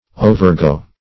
Search Result for " overgo" : The Collaborative International Dictionary of English v.0.48: Overgo \O`ver*go"\, v. t. [imp.